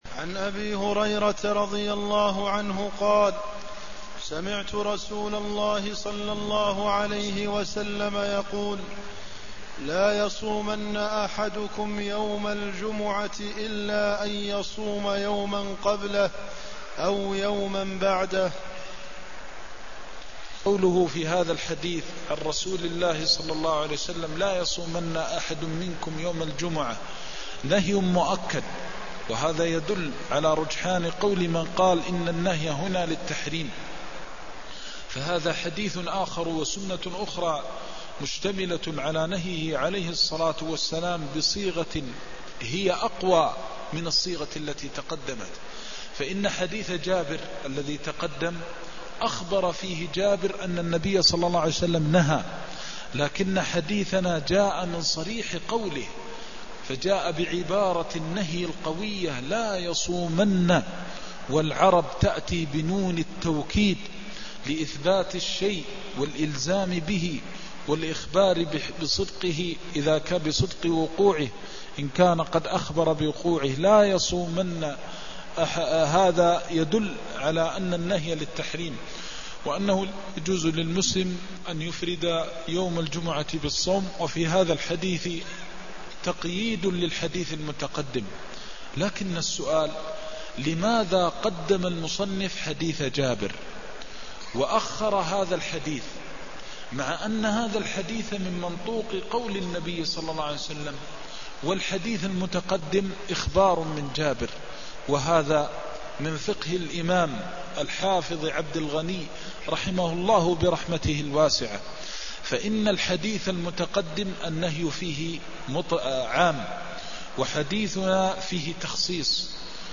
المكان: المسجد النبوي الشيخ: فضيلة الشيخ د. محمد بن محمد المختار فضيلة الشيخ د. محمد بن محمد المختار النهي عن صوم يوم الجمعة منفرداً (192)